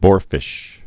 (bôrfĭsh)